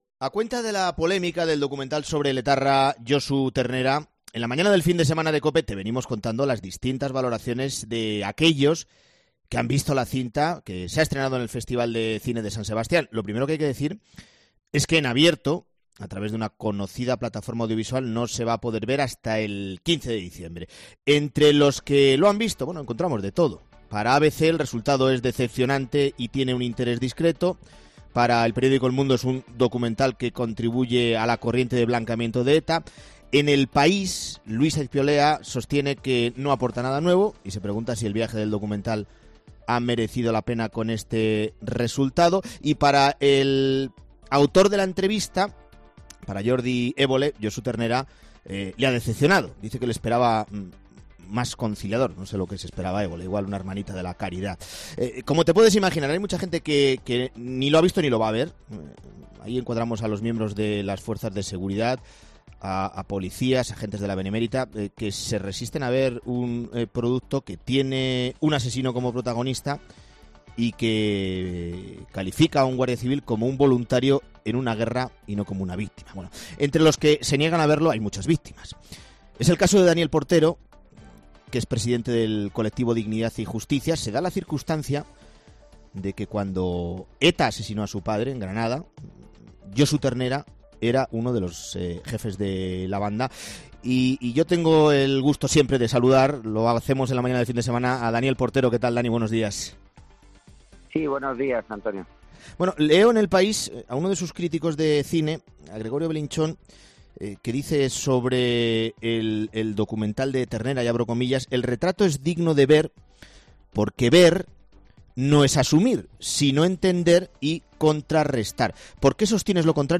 Una víctima de ETA, sobre el documental de Ternera, en COPE: "Somos un saco de boxeo, nos utilizan"